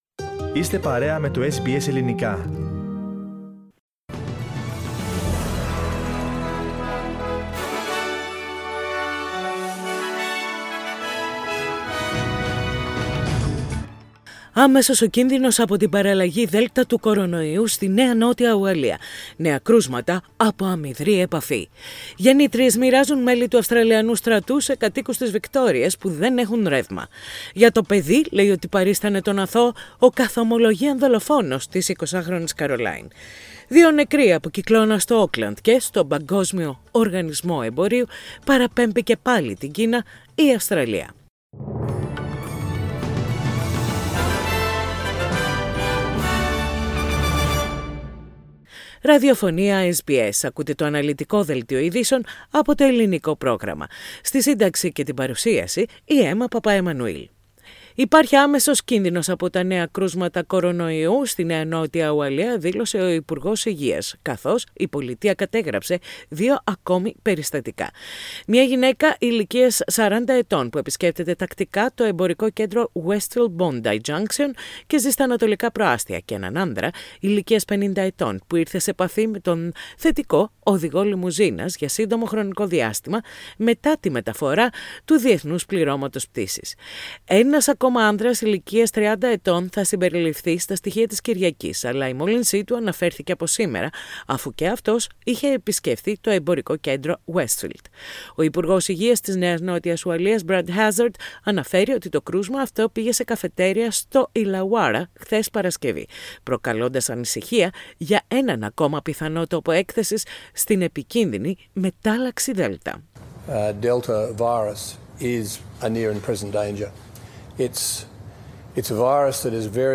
Ειδήσεις στα Ελληνικά - Σάββατο 19.6.21
Οι κυριότερες ειδήσεις της ημέρας από το Ελληνικό πρόγραμμα της ραδιοφωνίας SBS.